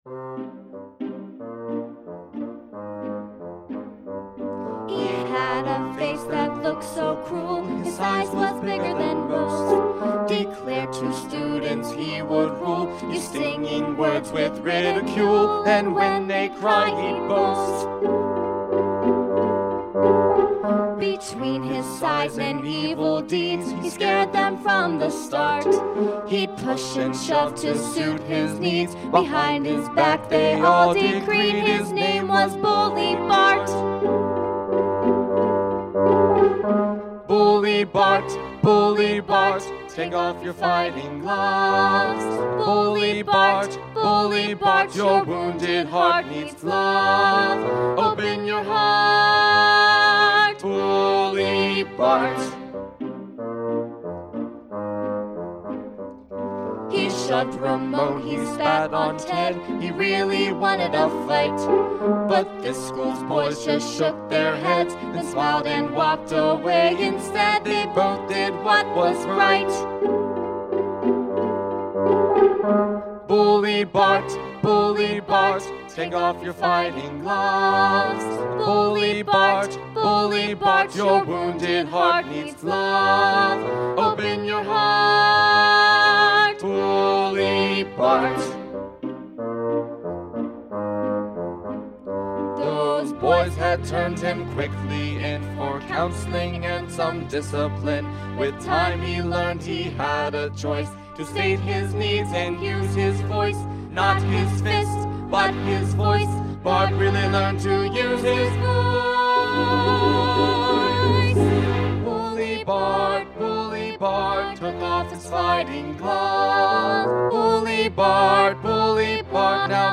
MP3 (with singing)